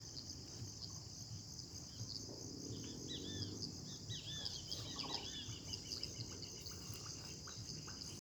Ñacundá (Chordeiles nacunda)
Nombre en inglés: Nacunda Nighthawk
Condición: Silvestre
Certeza: Observada, Vocalización Grabada